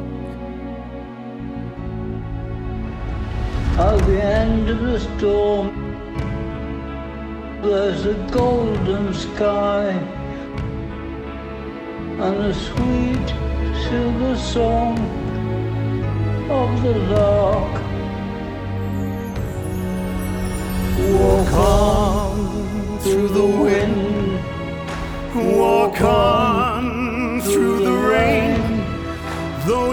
• Vocal